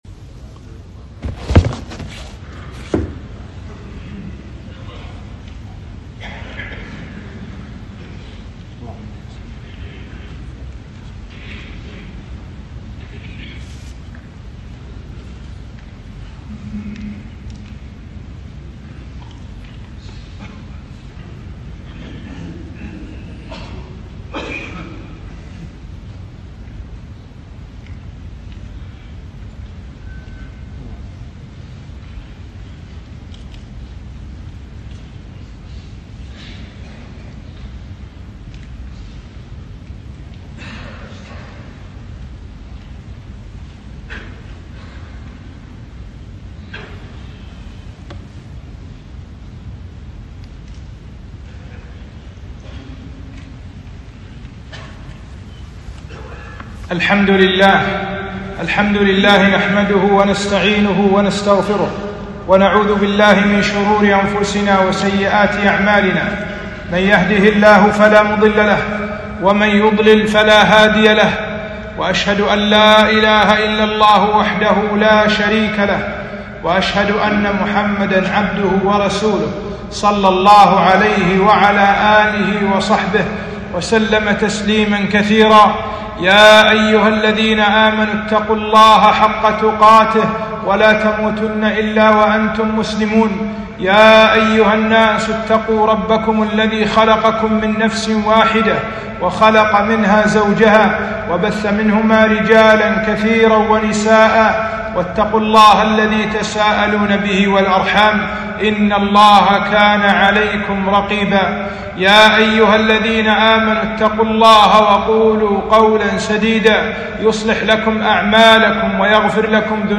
خطبة - التحذير من الفتن